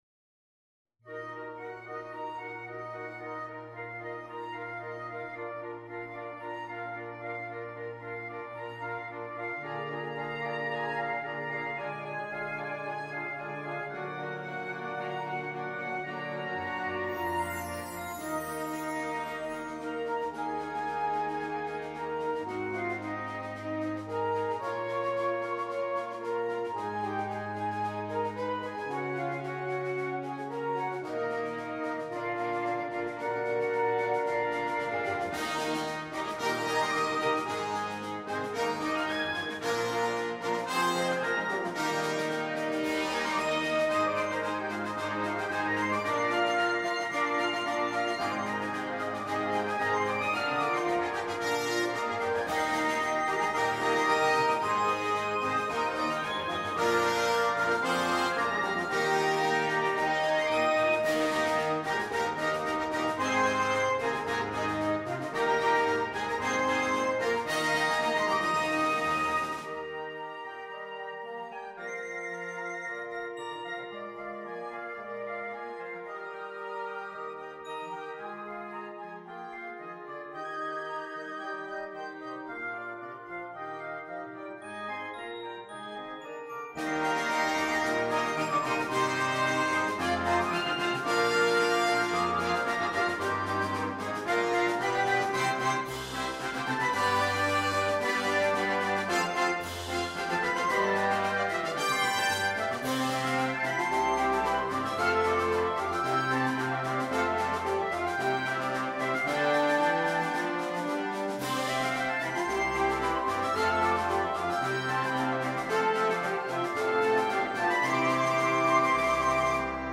2. Orchestre d'Harmonie
sans instrument solo
Musique légère